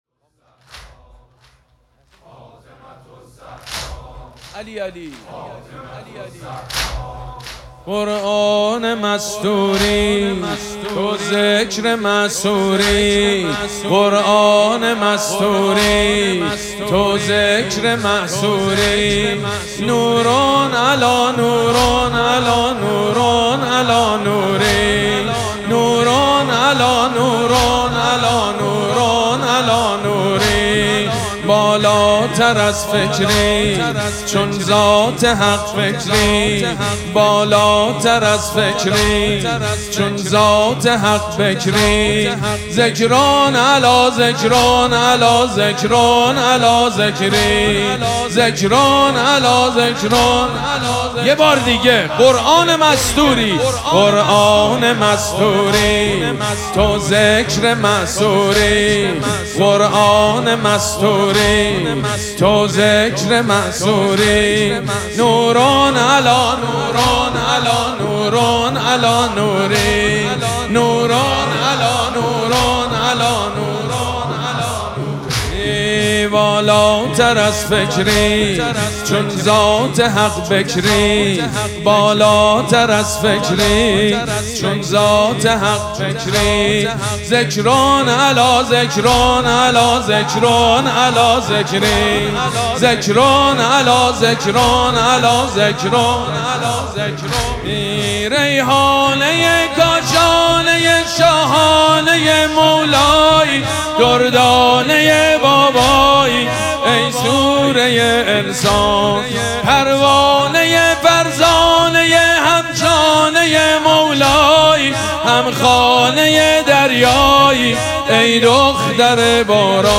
شب سوم مراسم عزاداری دهه دوم فاطمیه ۱۴۴۶
حسینیه ریحانه الحسین سلام الله علیها
حاج سید مجید بنی فاطمه